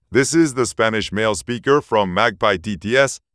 🔉ES-US.Male.Male-1
ES-US.Male.Male-1_MagpieTTS.wav